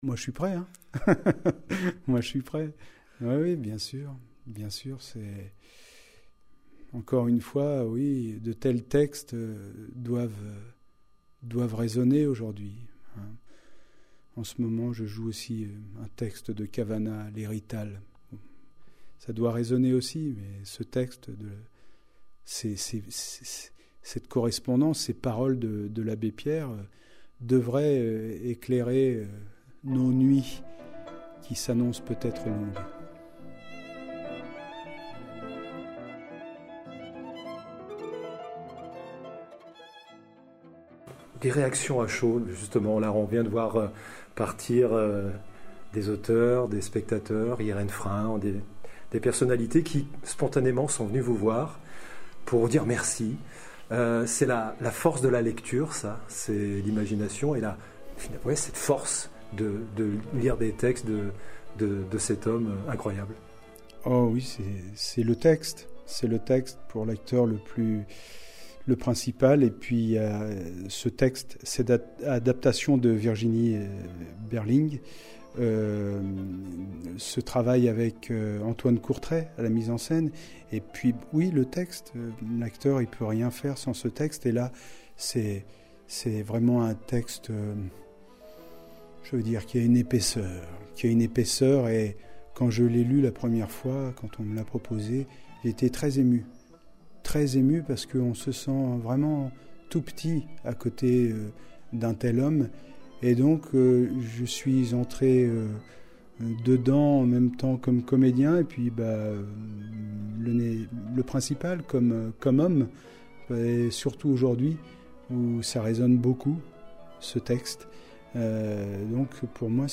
Le festival de la correspondance de Grignan 2024 a été marqué par un moment d'émotion intense avec la lecture-spectacle de Bruno Putzulu intitulée « L’éclat de rire d’un gosse de 84 ans et demi », basée sur la correspondance de l’Abbé Pierre.
Ces mots de Bruno Putzulu, lors de l'interview qui a suivi sa prestation, témoignent de la profondeur du texte.